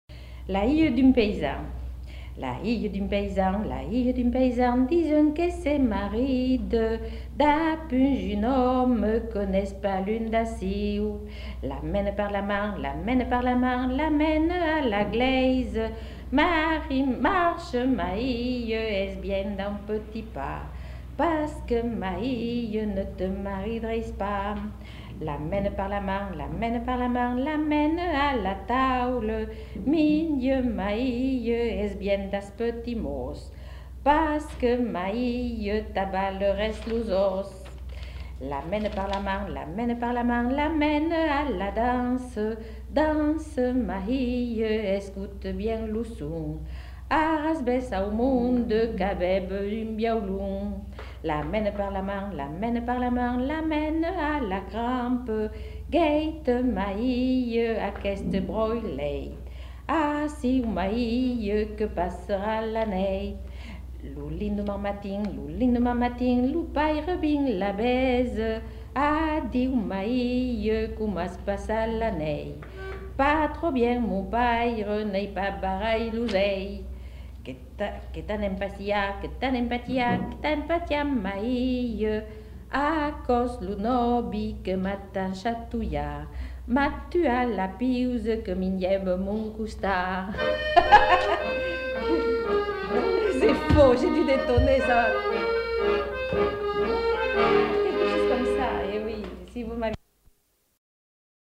Lieu : La Réole
Genre : chant
Effectif : 1
Type de voix : voix de femme
Production du son : chanté